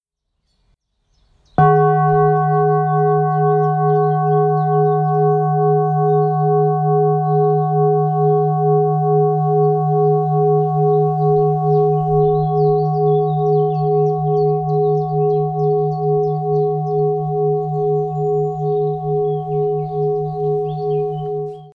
417 Hz - Net 21
Genre: Klang.
Klangschale-417-Hz-36-cm.mp3